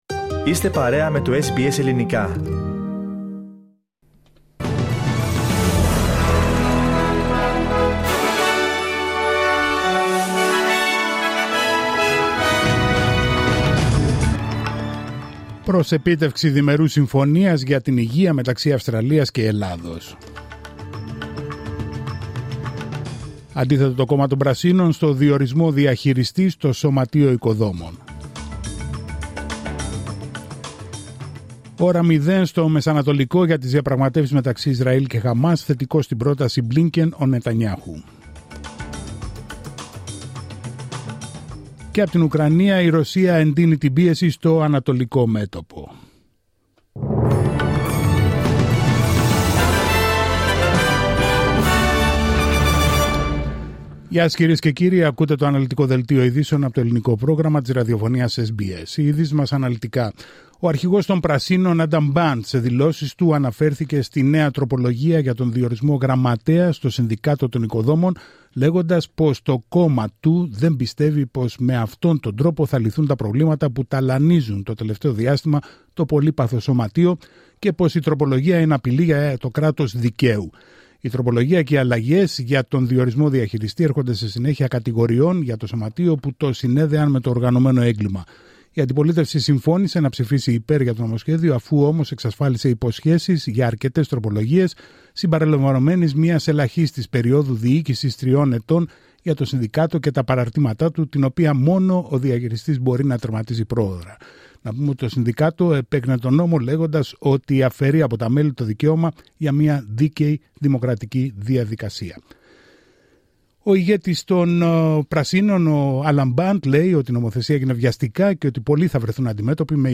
Δελτίο ειδήσεων Τρίτη 20 Αυγούστου 2024